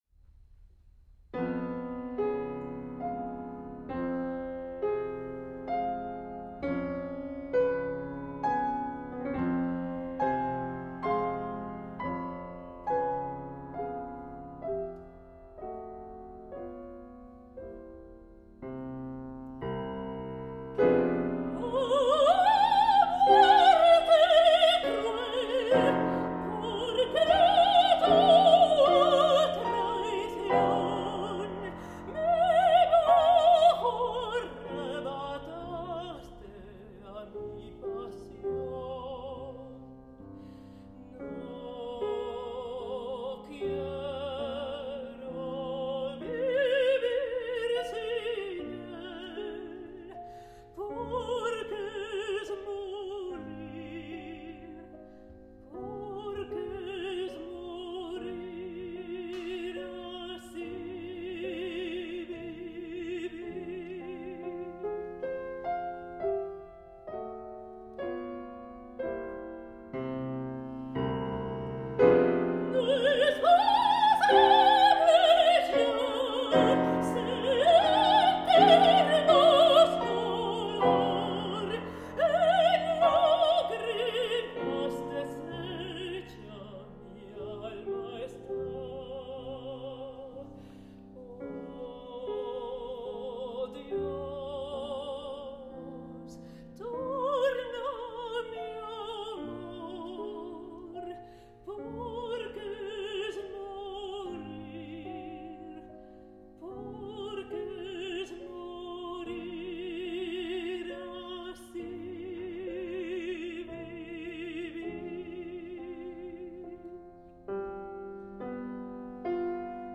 Chanteuse Mezzo-Soprano Opera